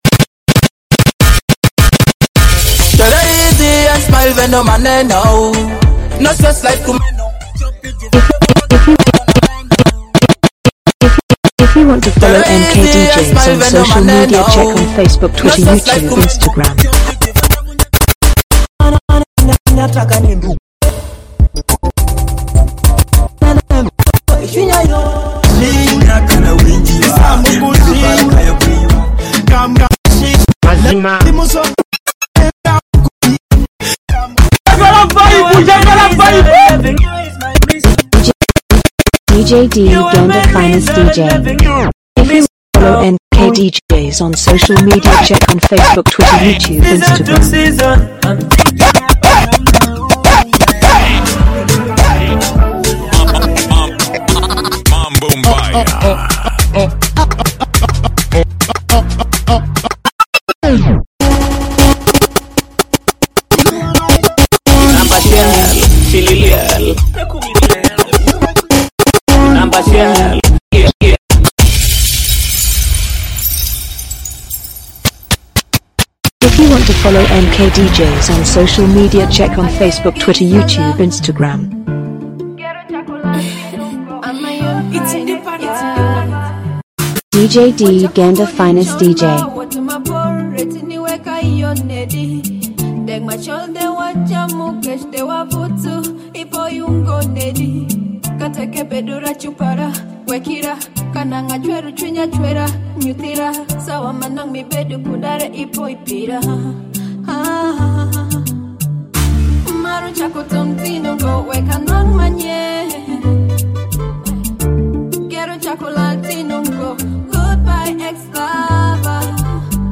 Latest Alur Music